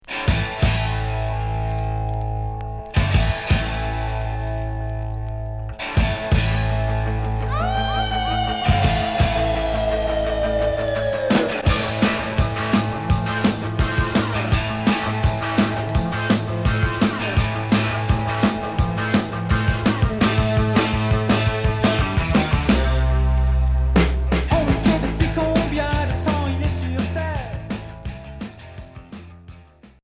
Punk rock Sixième 45t retour à l'accueil